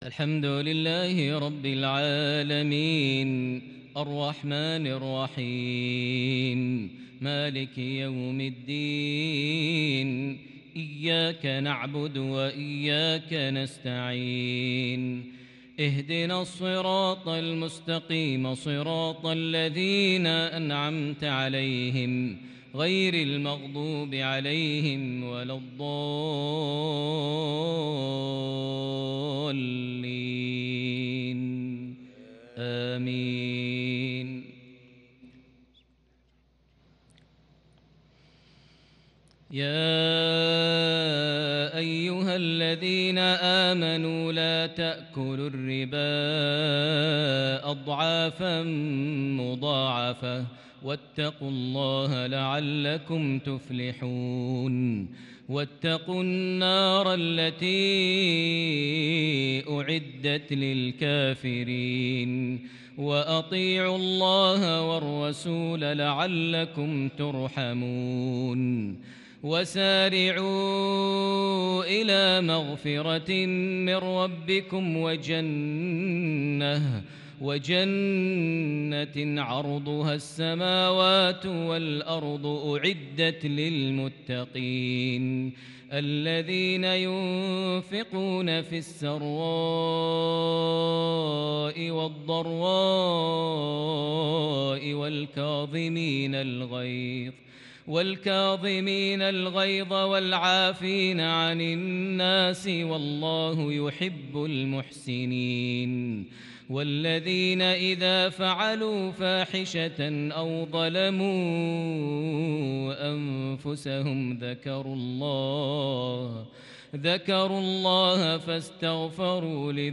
عشائية تتجلّى بالإبداع من سورة آل عمران (130-144) | 26 ربيع الأول 1442هـ > 1442 هـ > الفروض - تلاوات ماهر المعيقلي